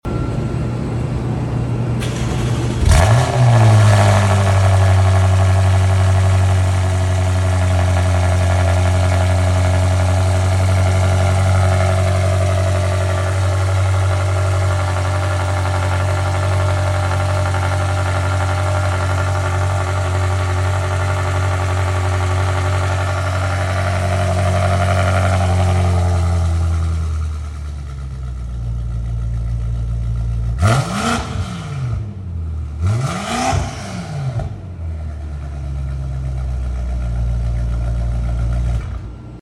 M8 comp. Cold start and sound effects free download
Cold start and Mp3 Sound Effect M8 comp. Cold start and small revs for ya 🤤 M8 Comp.